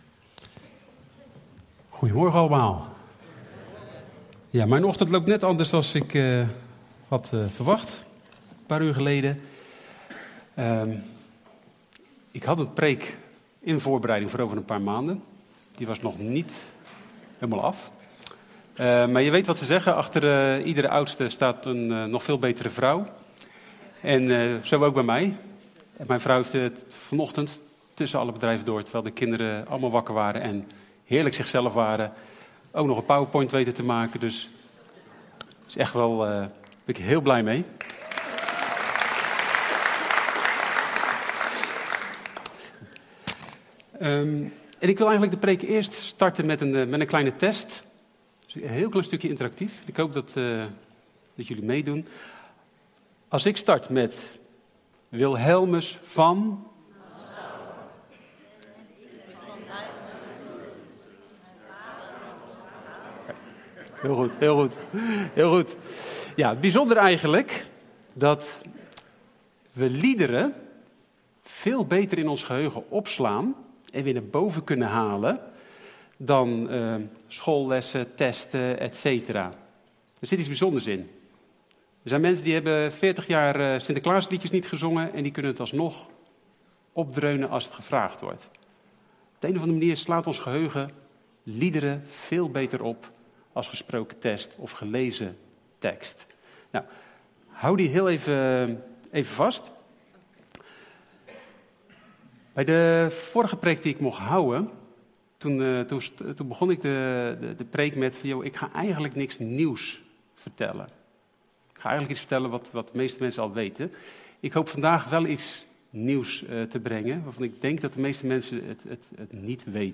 Preek-7-januari-2024.mp3